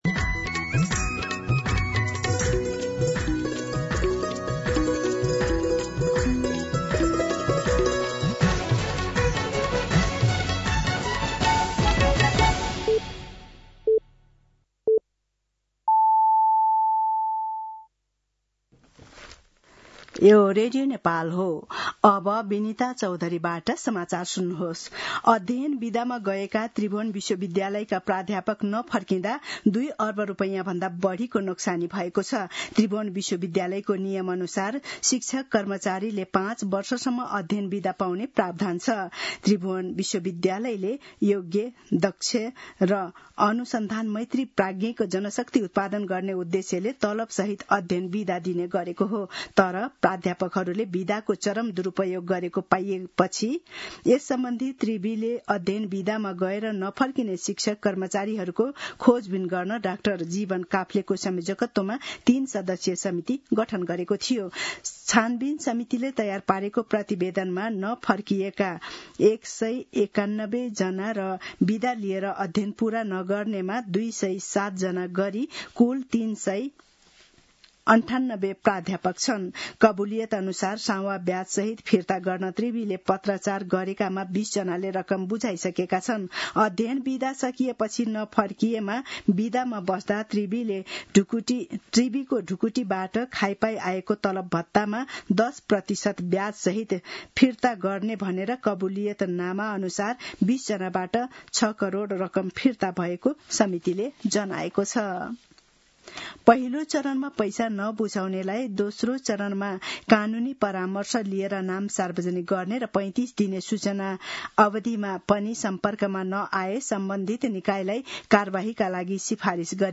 दिउँसो १ बजेको नेपाली समाचार : ६ वैशाख , २०८३